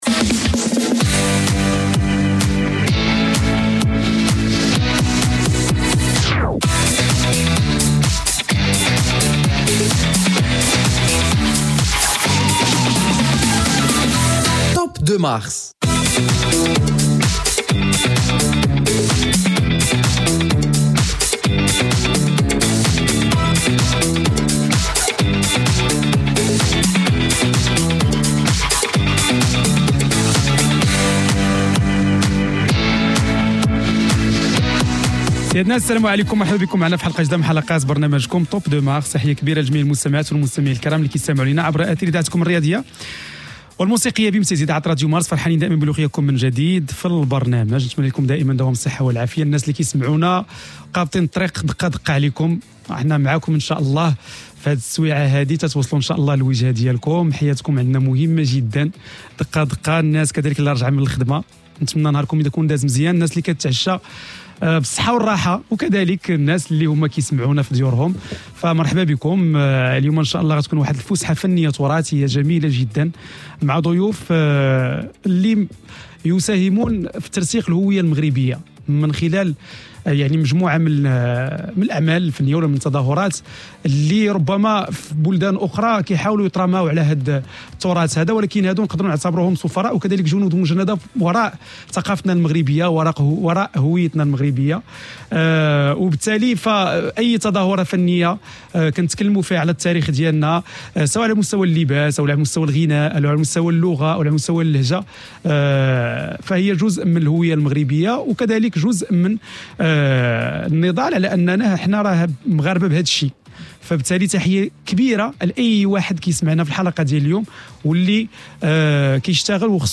برنامج موسيقي بامتياز بلمسة مغربية، ترفيه، مسابقات و إهداءت طيلة ساعة ونصف على راديو مارس